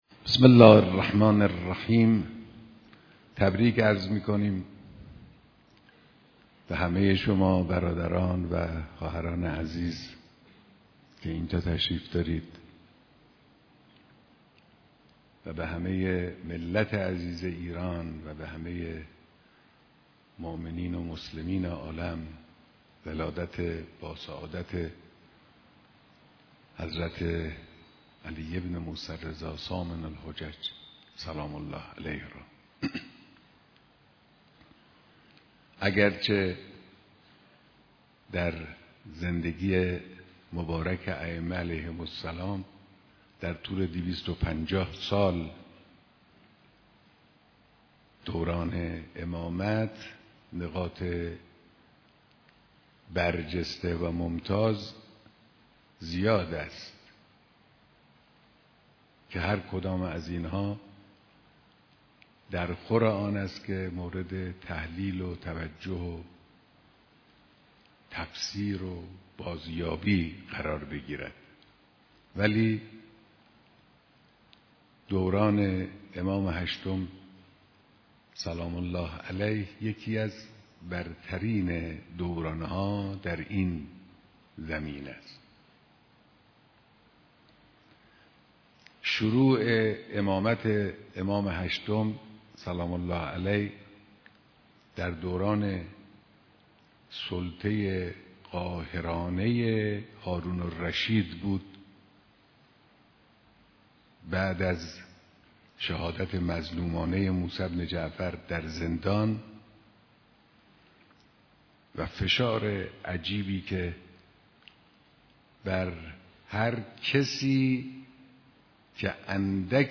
بیانات در دیدار کارگزاران حج